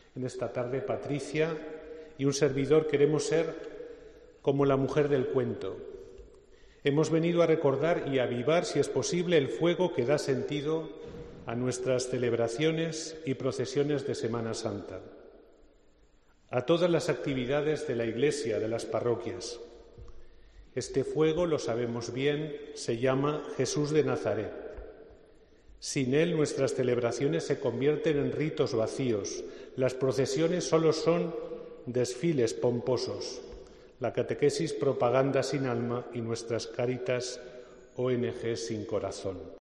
La Iglesia Santo Domingo ha acogido el pregón de la Semana Santa